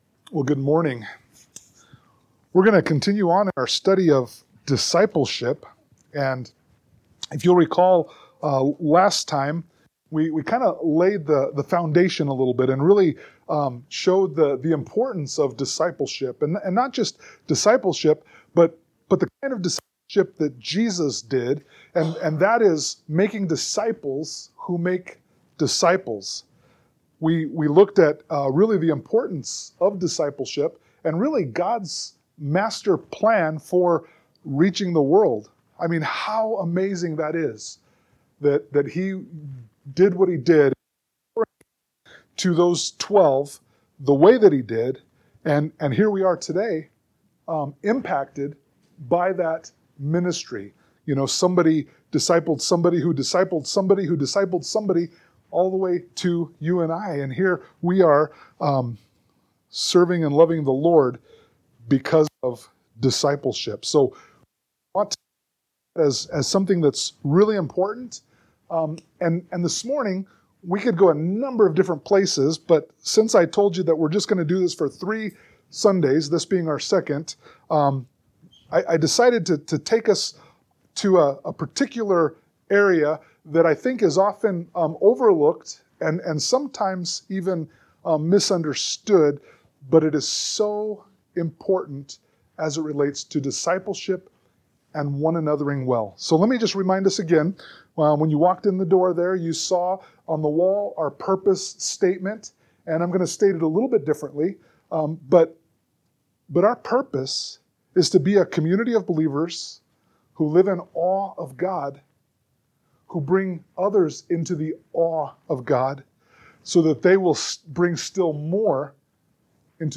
Passage: 1 John 1:5-10 Service Type: Sunday Morning Worship